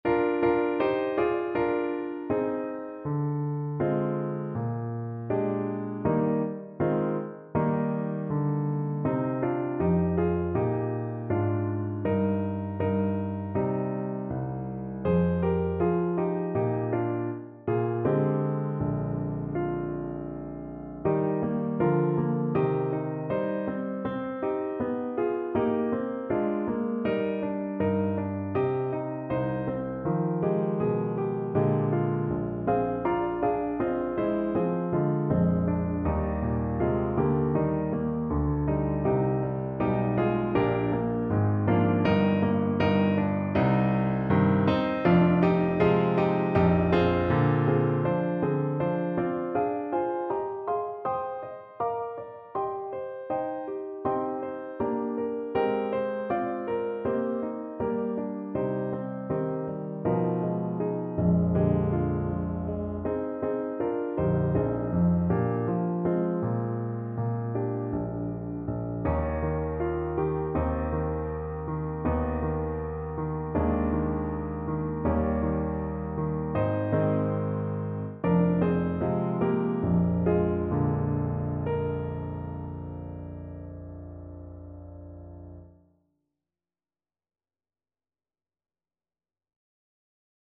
Alto Saxophone
2/4 (View more 2/4 Music)
Moderato =80
Traditional (View more Traditional Saxophone Music)